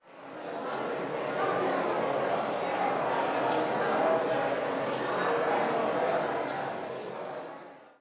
Sfx Orchestra Warms Up Shorter Sound Effect
sfx-orchestra-warms-up-shorter.mp3